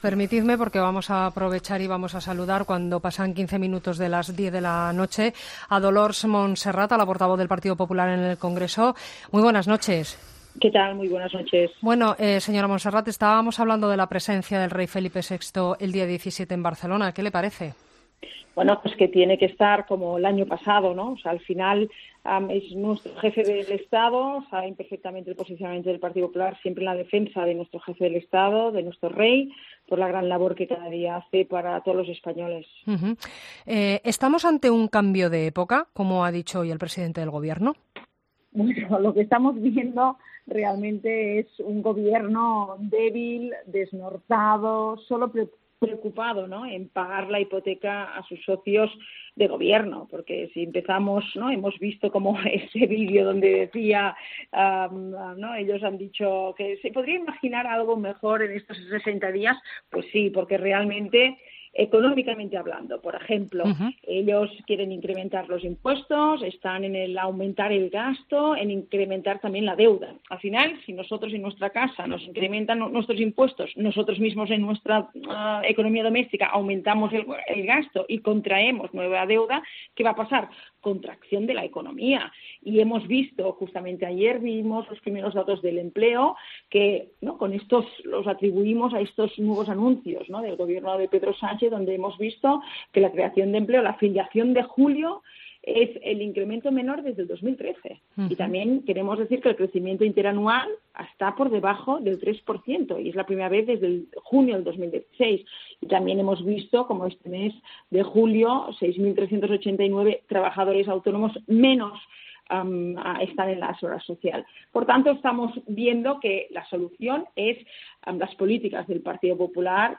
La portavoz del PP en el Congreso, Dolors Montserrat; y el secretario general de Ciudadanos, José Manuel Villegas; han hablado en 'La Linterna' de...